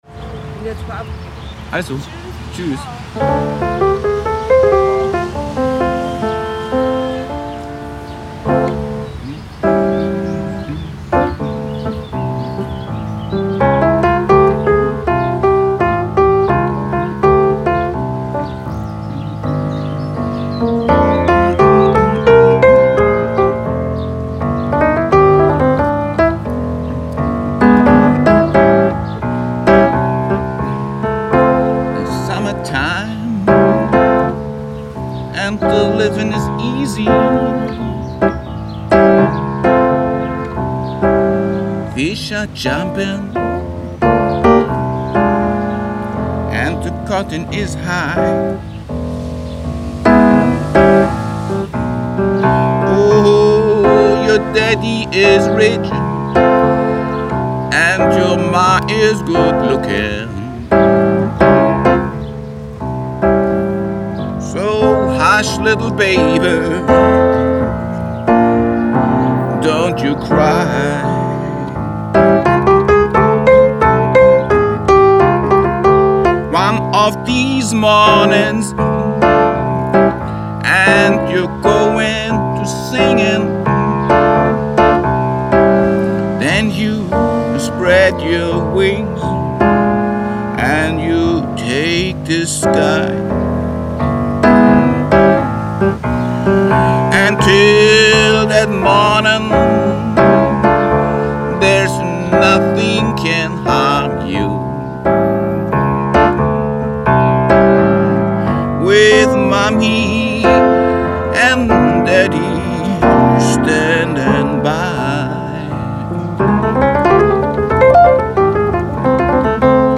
Schillingbrücke: